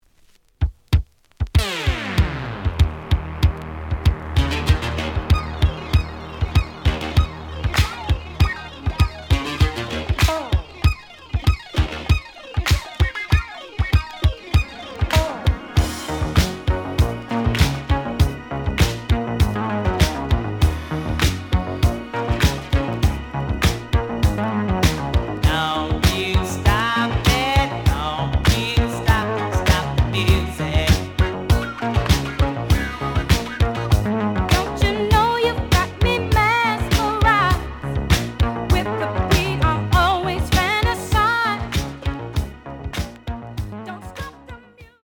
試聴は実際のレコードから録音しています。
The audio sample is recorded from the actual item.
●Genre: Disco